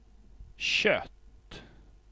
Uttal
Synonymer hull Uttal Okänd accent: IPA: /ɕœtː/ Ordet hittades på dessa språk: svenska Översättning Substantiv 1. et Artikel: ett .